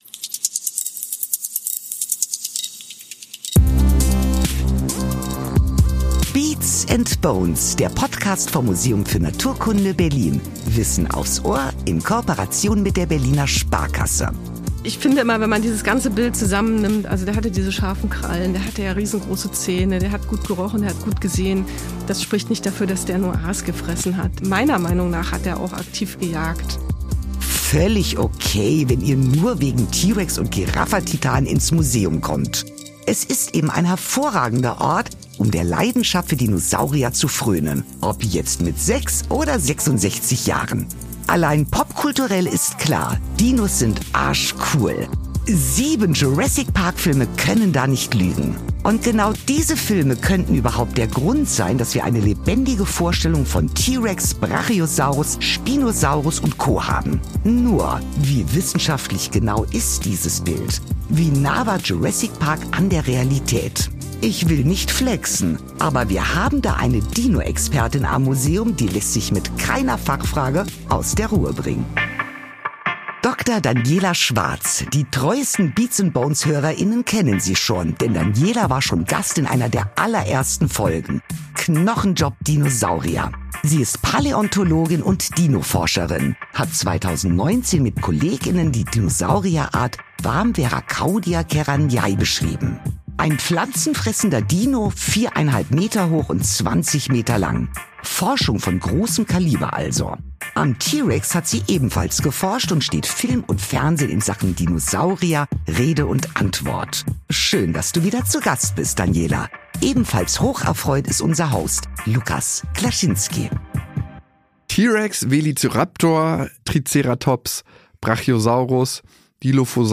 Was sonst im Museum hinter verschlossenen Türen passiert, bringen wir mit dem Beats & Bones Podcast ans Licht.